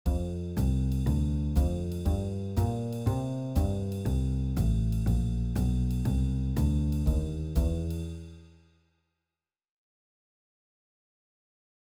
4ビートは、その名の通り「1小節に4つのビート（拍）」を感じるスタイル。
ウォーキングベース（ベースが4分音符で歩くように進行）が鳴っている時です。
一緒に4ビートを演奏したら下記のような感じになります
4ビート.wav